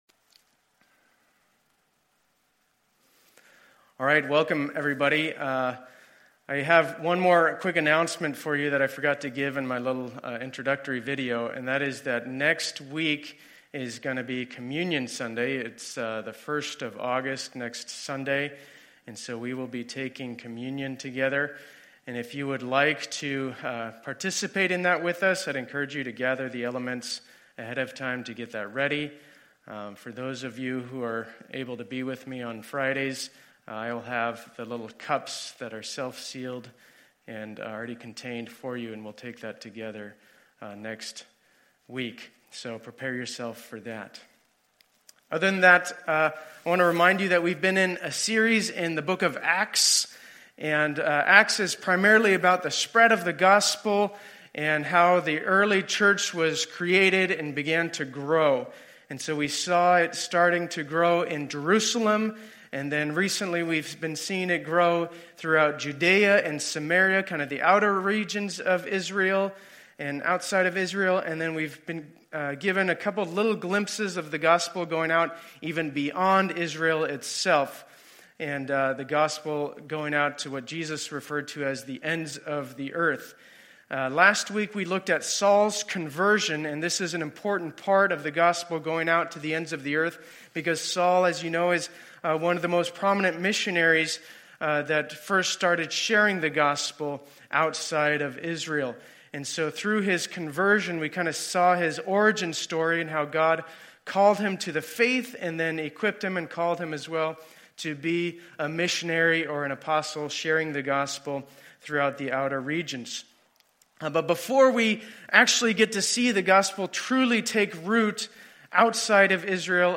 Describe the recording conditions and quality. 2020-07-26 Sunday Service